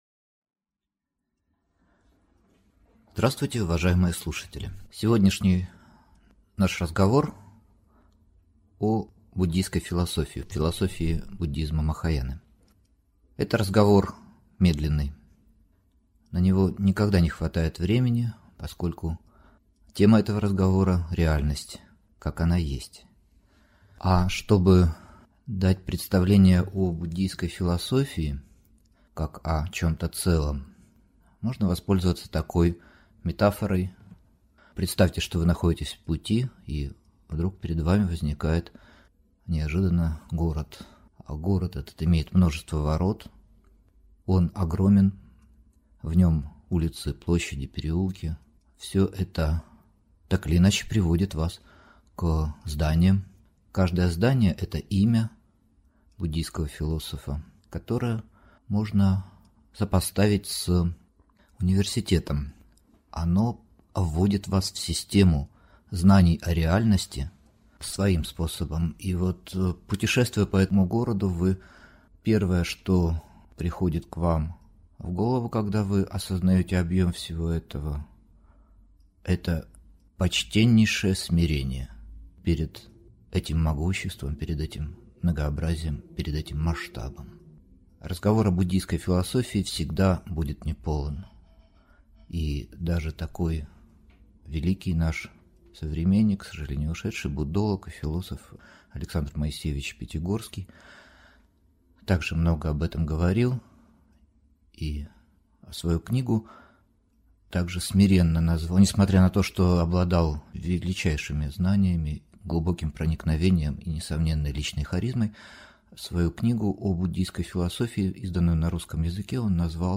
Аудиокнига Развитие философии в махаяне | Библиотека аудиокниг